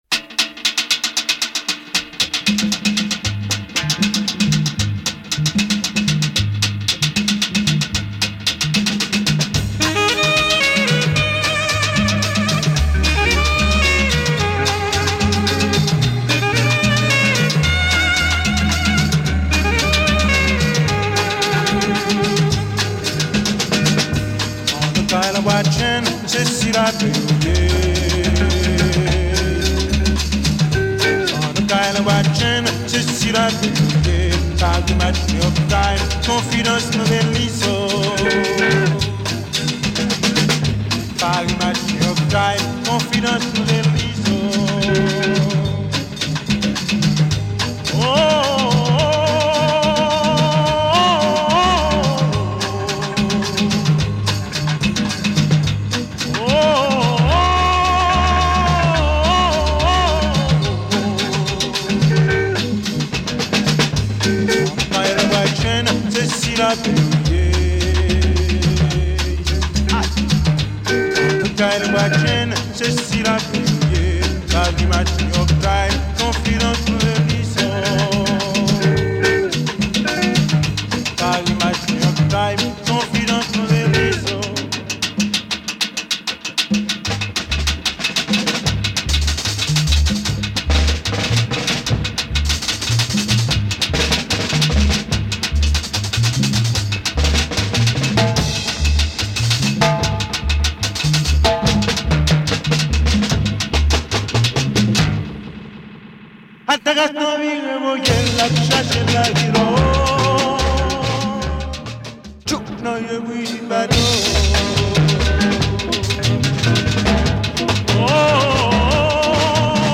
Early 70's album by the Haitian band